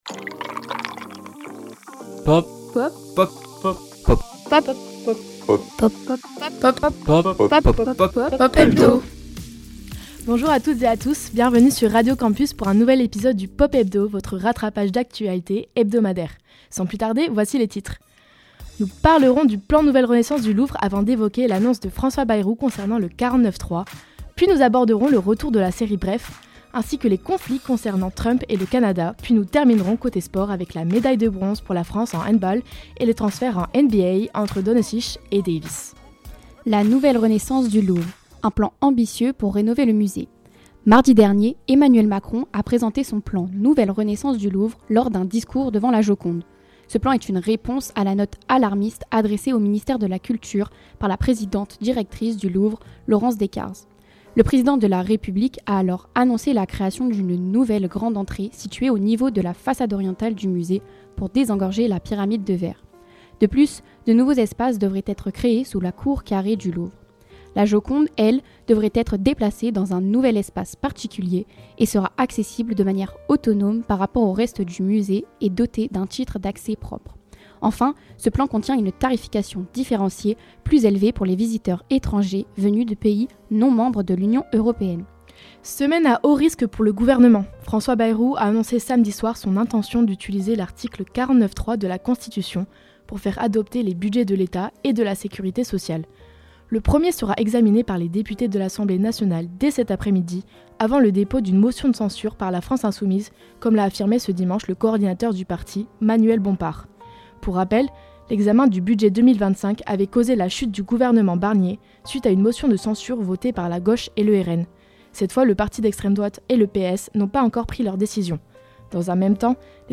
Programme à retrouver en direct tous les lundis entre 12h et 13h sur Radio Campus Bordeaux (merci à eux), et en rediffusion sur notre site Web et Spotify !